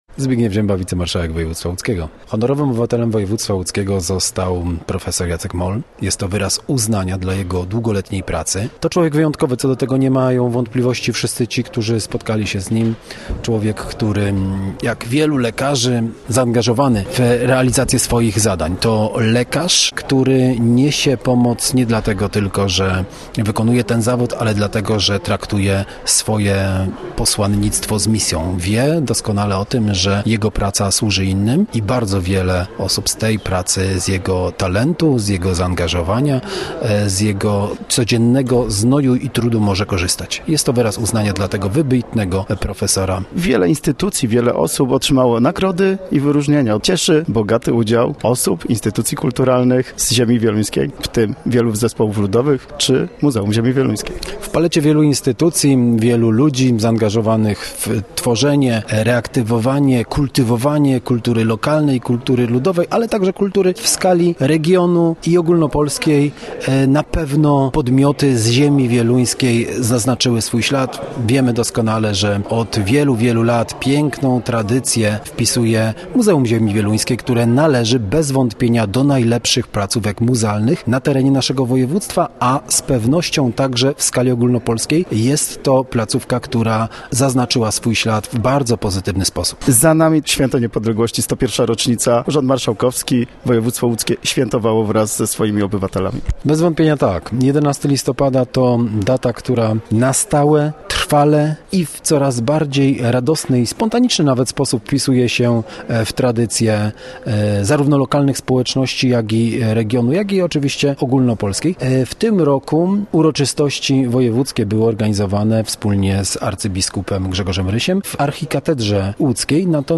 Gościem Radia ZW był Zbigniew Ziemba, wicemarszałek Województwa Łódzkiego